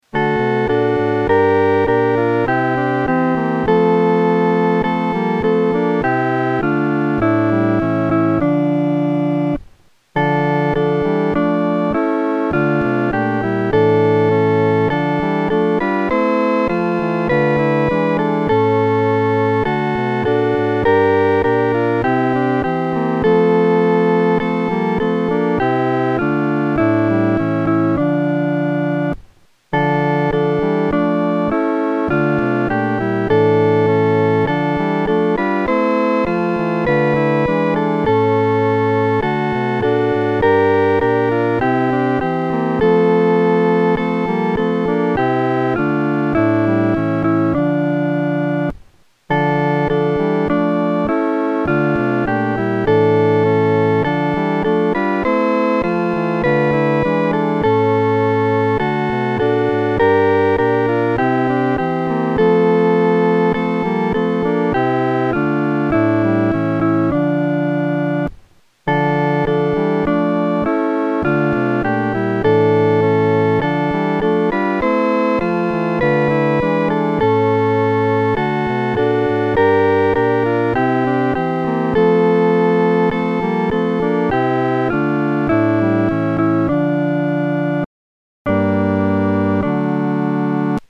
伴奏
四声
这首诗歌宜用不太慢的中速弹唱。